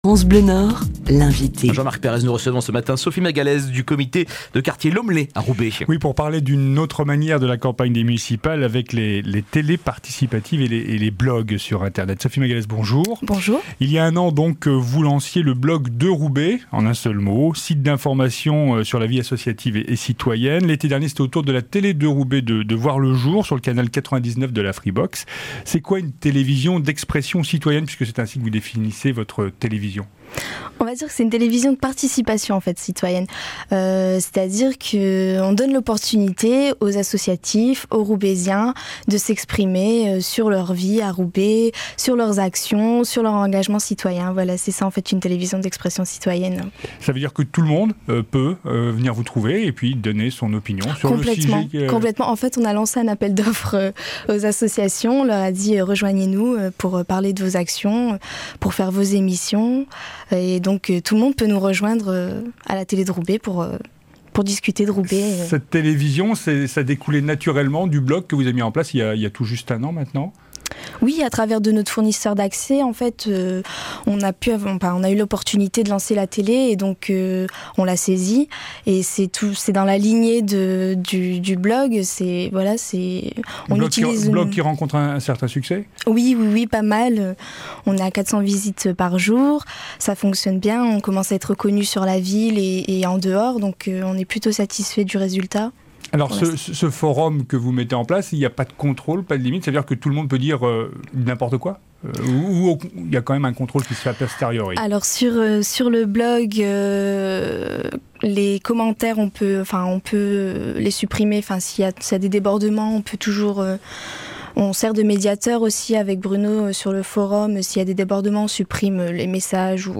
L’équipe du Comité de quartier de l’Hommelet était dans les studios de France Bleu Nord.